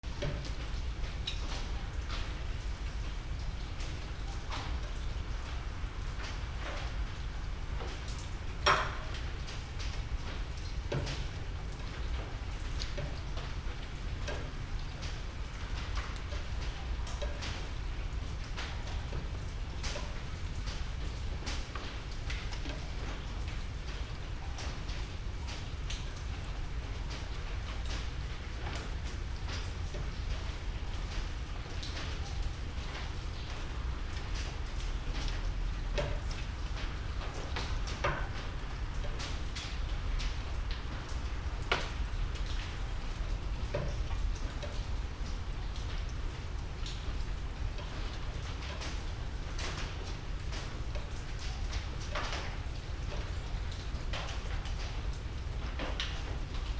/ A｜環境音(天候) / A-10 ｜雨
雨上がり バイノーラル